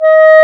Index of /90_sSampleCDs/Roland LCDP04 Orchestral Winds/CMB_Wind Sects 1/CMB_Wind Sect 4
WND CLAR D#5.wav